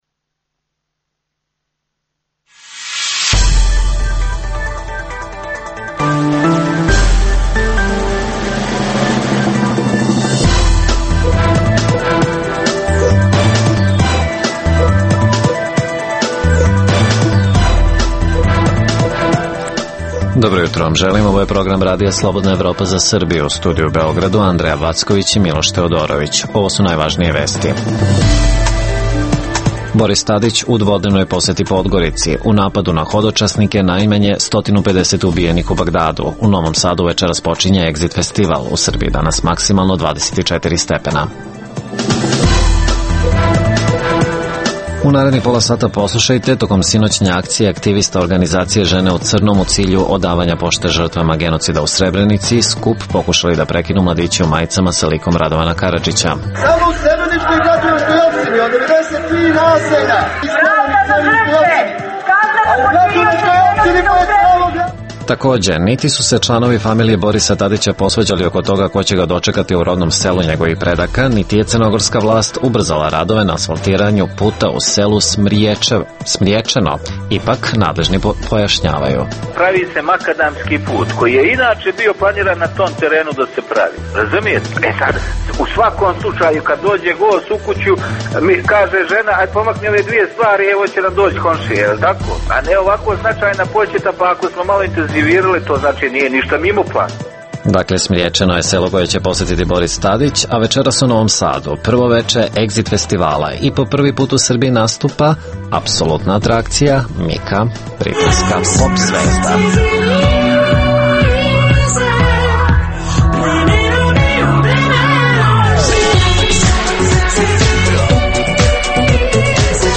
Takođe, poslušajte reportažu o akciji podsećanja na srebreničke žrtve koja je održana u centru Beograda.